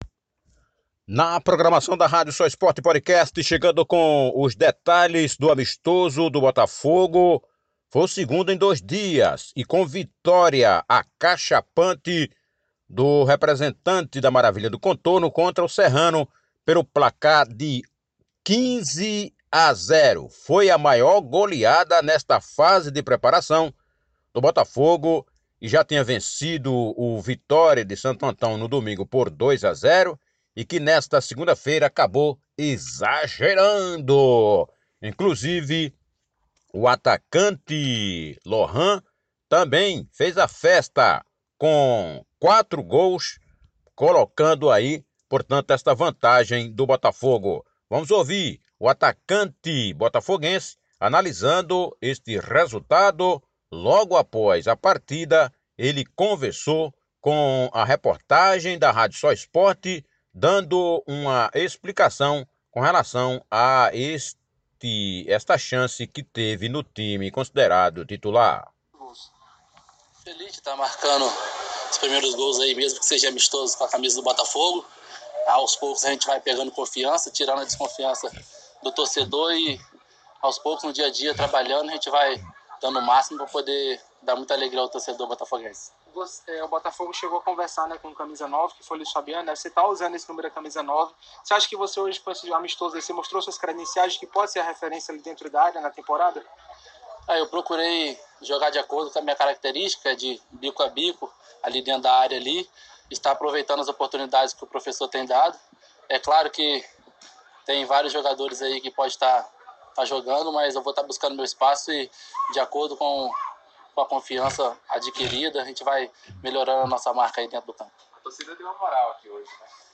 A Rádio Soesporte Podcast com informações esportivas da Paraíba destaca entrevista